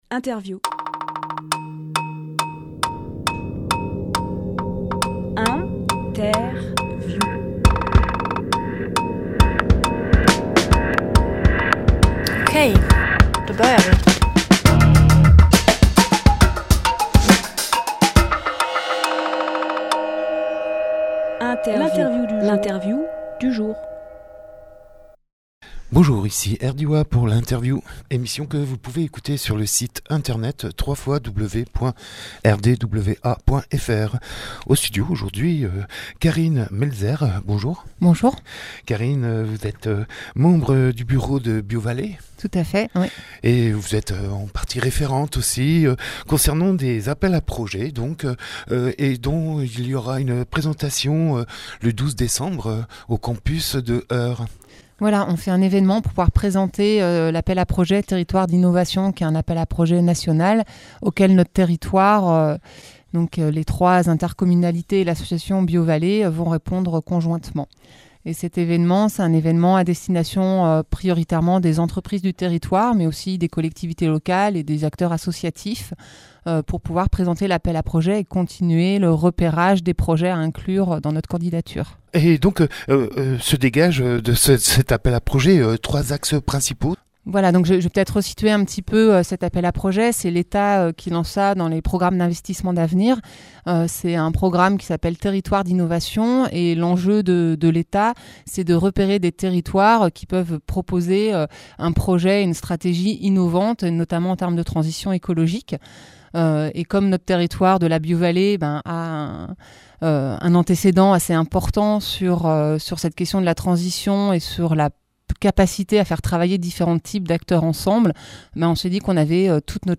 Emission - Interview Appel à projet de territoire d’innovations Publié le 6 décembre 2018 Partager sur…
Lieu : Studio RDWA